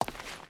Stone Walk 1.wav